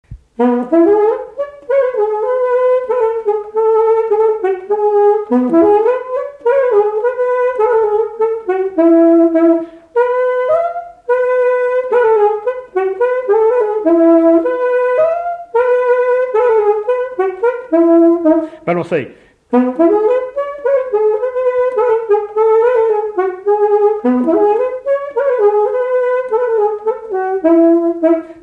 1è figure du quadrille tout petit
Mareuil-sur-Lay
Résumé instrumental
Pièce musicale inédite